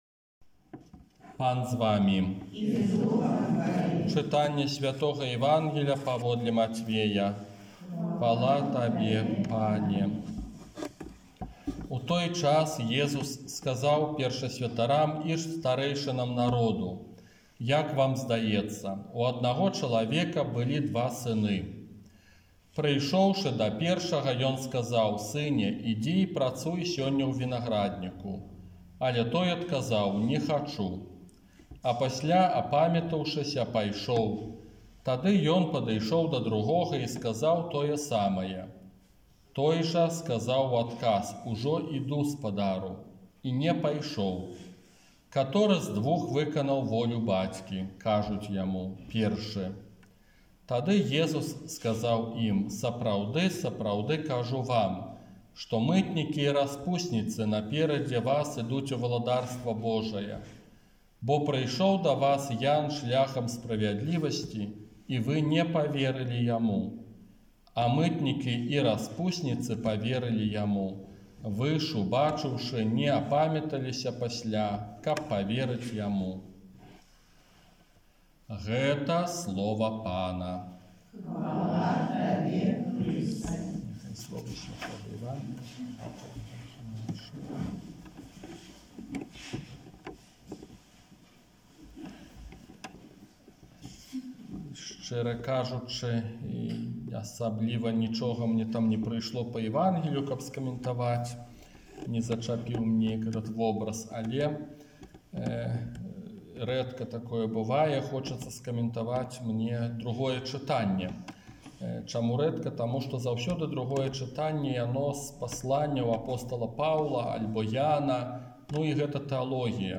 ОРША - ПАРАФІЯ СВЯТОГА ЯЗЭПА
Казанне на дваццаць шостую звычайную нядзелю